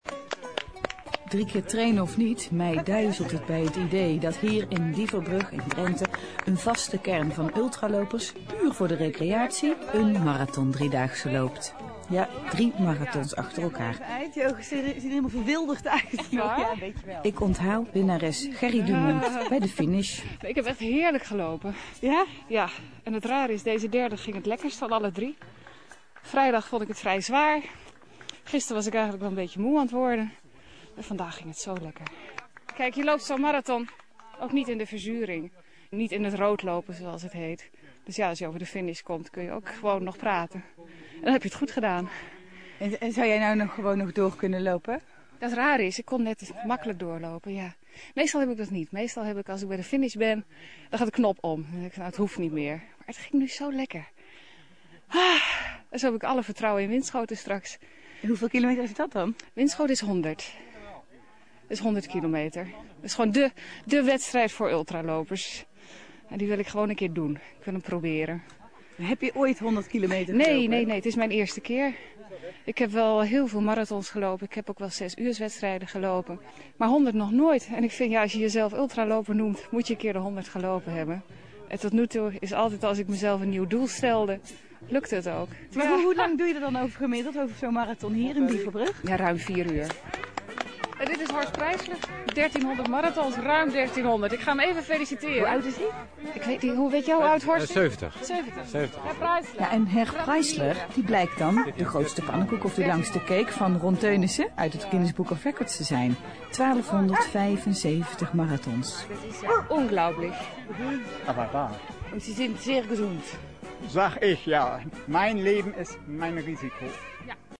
radio-interview (MP3, 900k)